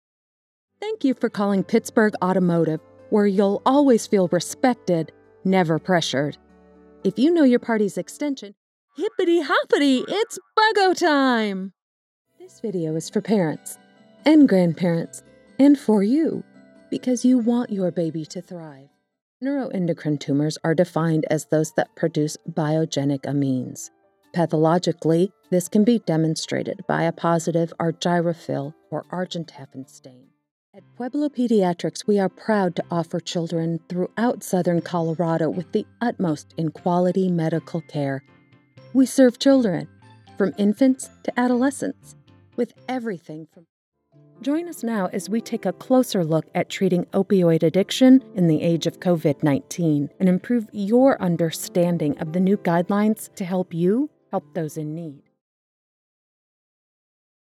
Female voice over artist, nurse, and dog mom for your voice over projects!
IVR, eLearning, Medical Narration, Commercial
Southern; midwestern
I have my own home studio and do my own post-production.